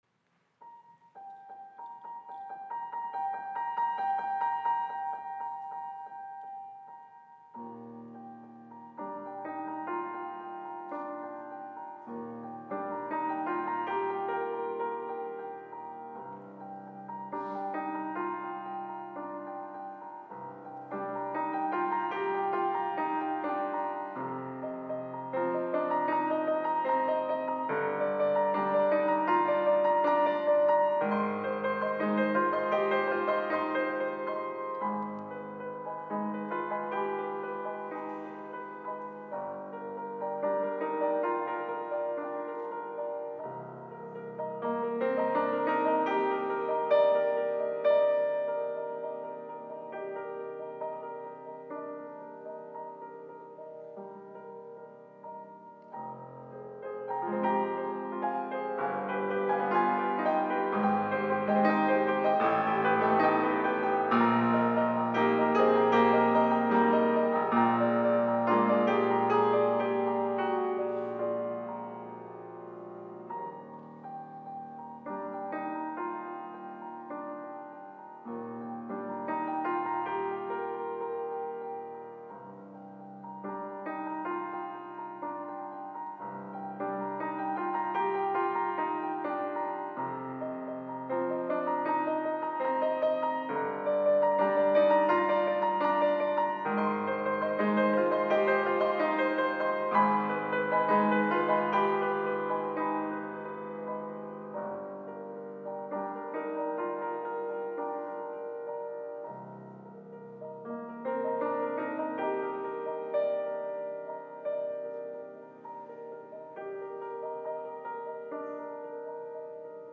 Instrumentación: piano solo